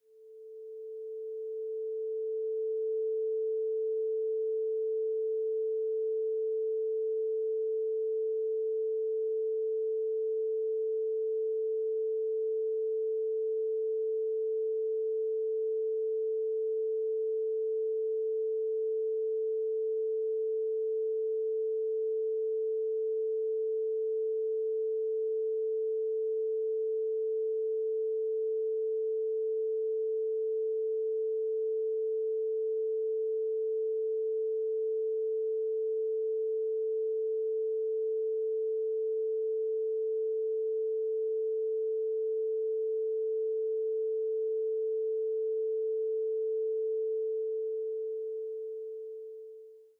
Duration: 0:50 · Genre: Romantic · 128kbps MP3